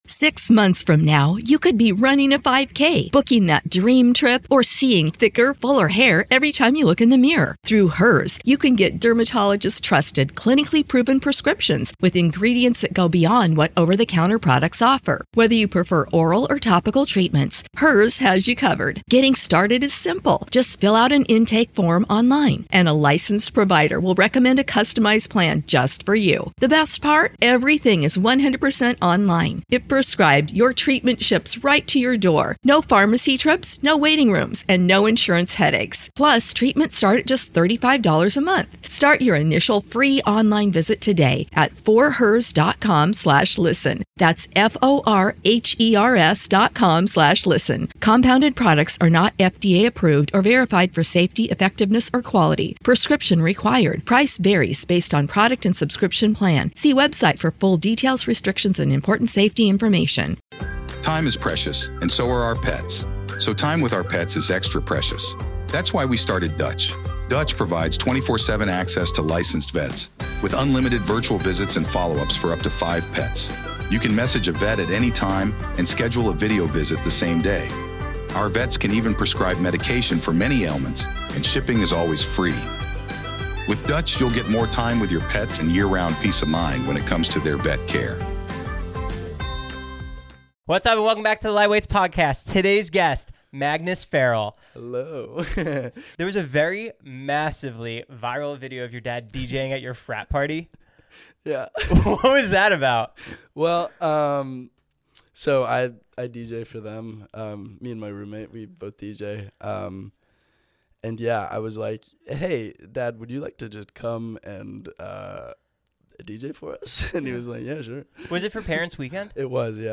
Society & Culture, Tv Reviews, Comedy Interviews, Film Interviews, Comedy, Tv & Film, Education, Hobbies, Music Commentary, Music Interviews, Relationships, Leisure, Health & Fitness, Self-improvement, Music, Fitness